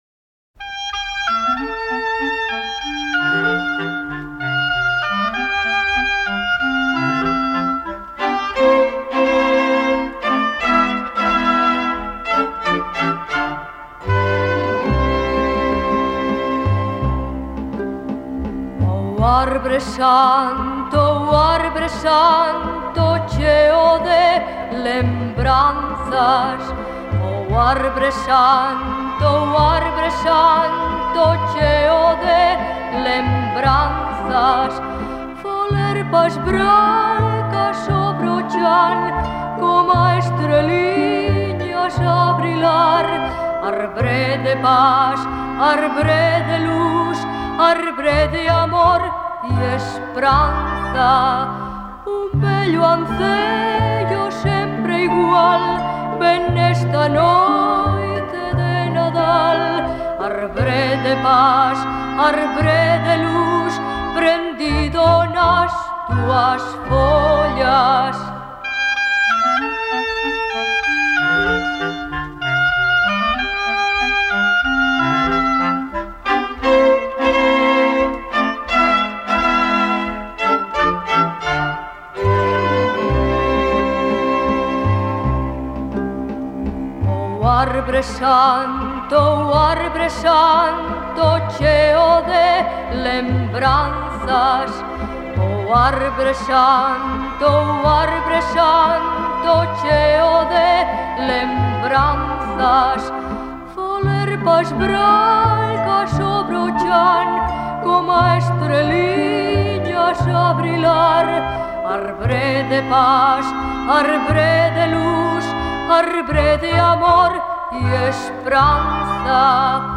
Popular Voz